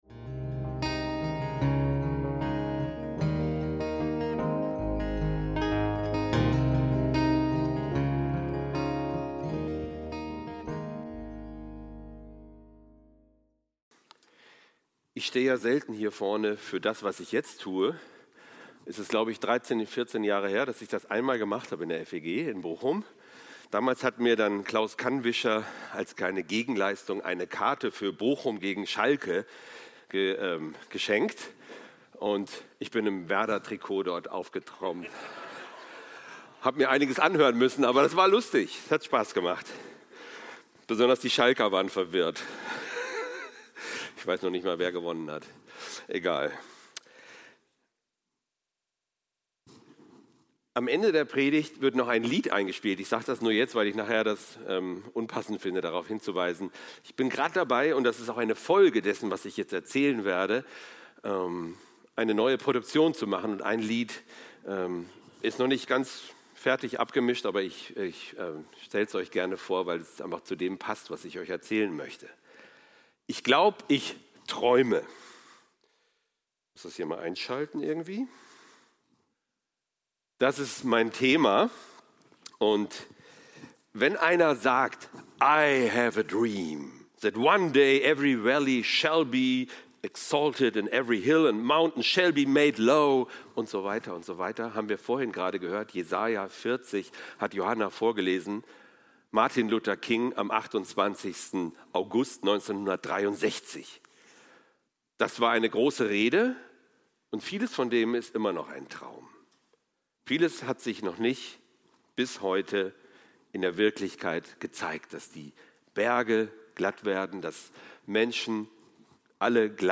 Ich glaub ich träume - Predigt vom 27.07.2025